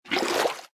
Minecraft Version Minecraft Version snapshot Latest Release | Latest Snapshot snapshot / assets / minecraft / sounds / item / bucket / fill1.ogg Compare With Compare With Latest Release | Latest Snapshot